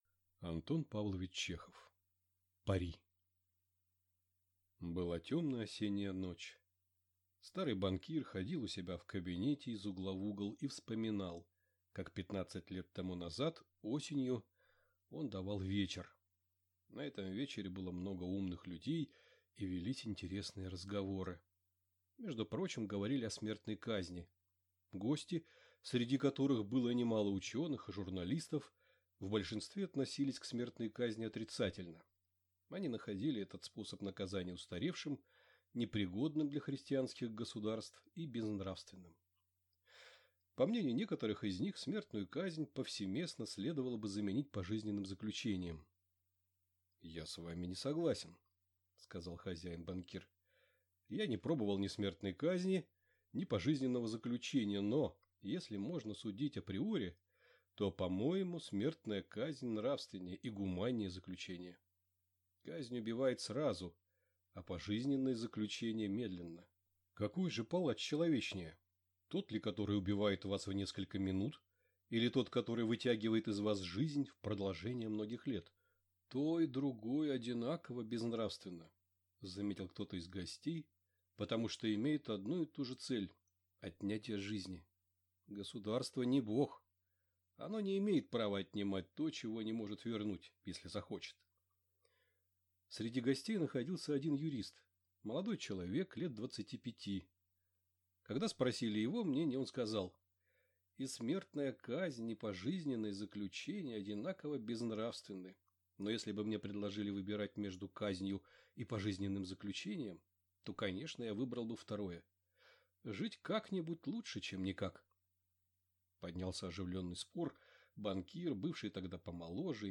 Аудиокнига Пари | Библиотека аудиокниг
Прослушать и бесплатно скачать фрагмент аудиокниги